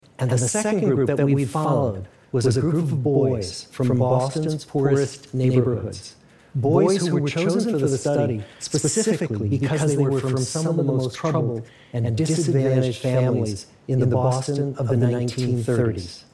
Latency-Demo-100ms.mp3